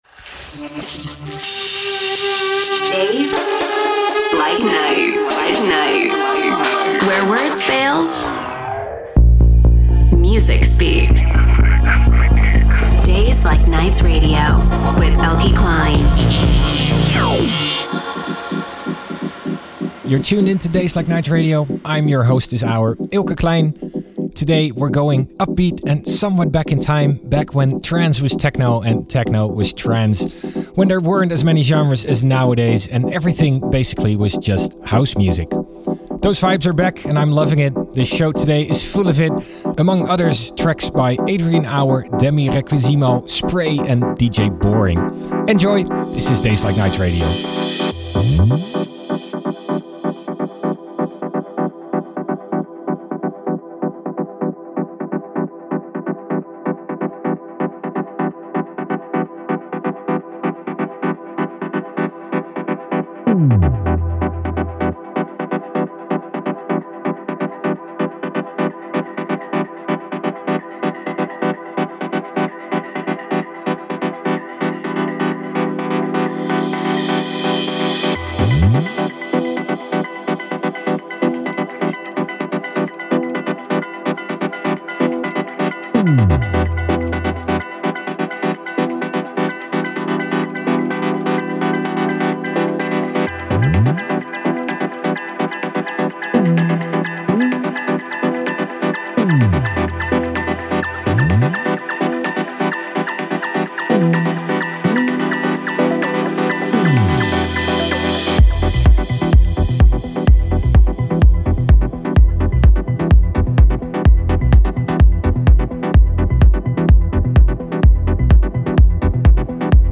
DJ Mix & Live Set